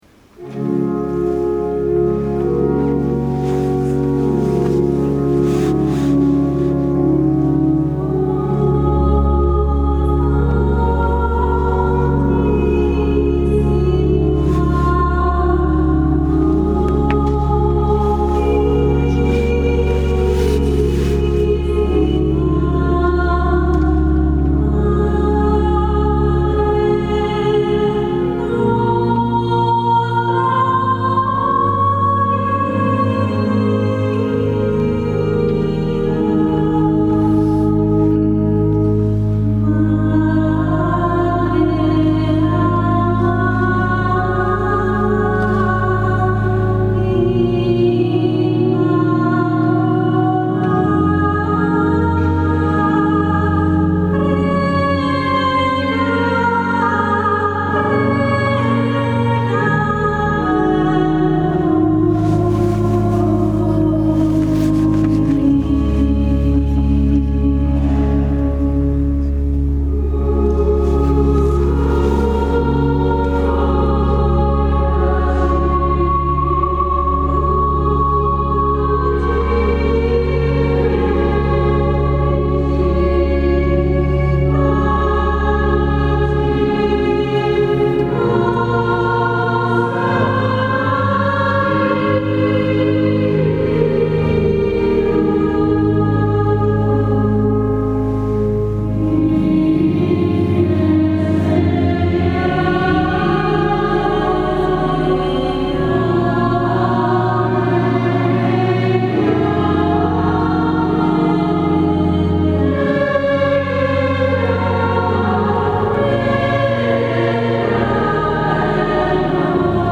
Sabato 07 ottobre 2017 la corale ha animato la S. Messa in occasione della festività della Madonna del Rosario.